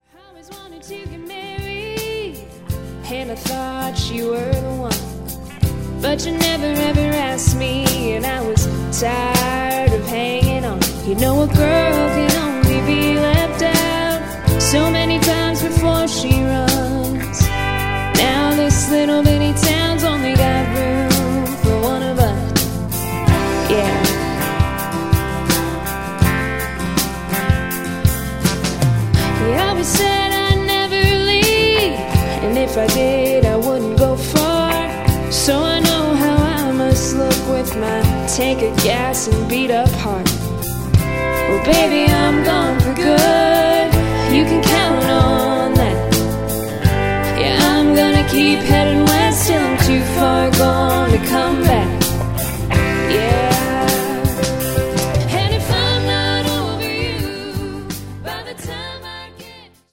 Cover
Lead Vocals